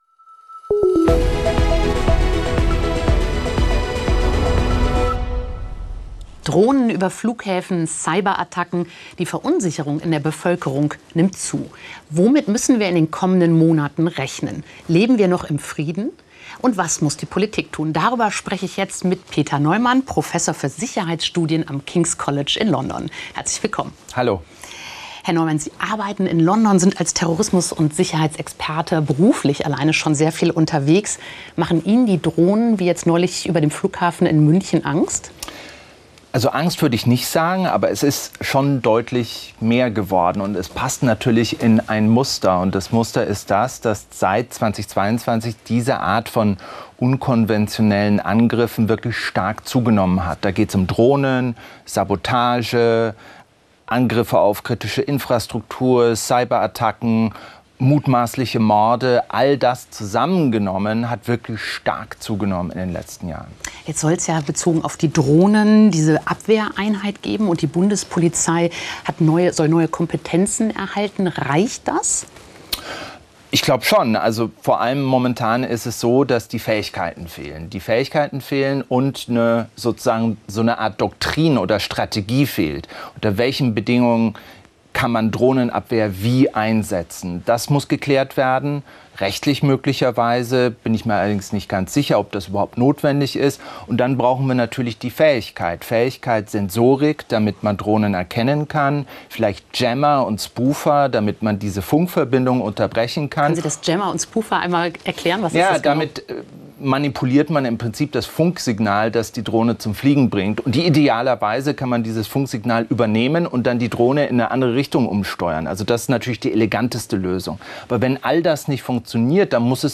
Politikwissenschaftler Prof. Peter R. Neumann zu Gast